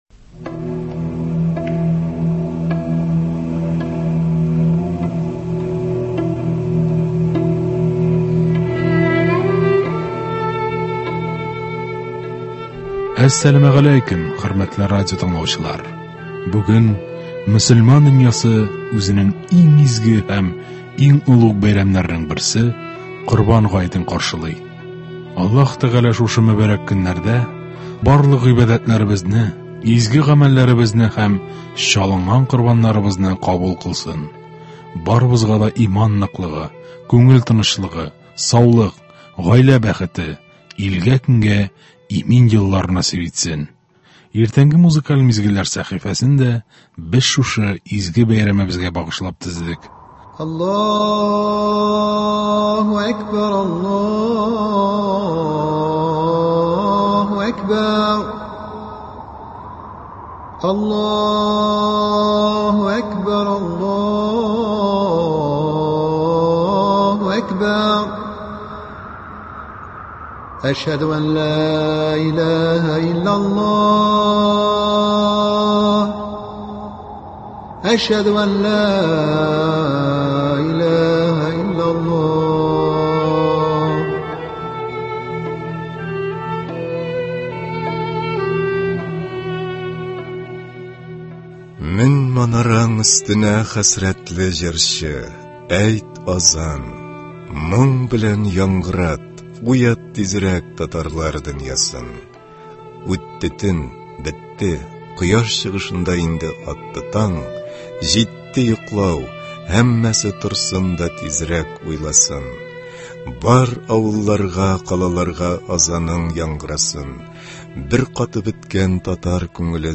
Иртәнге концерт.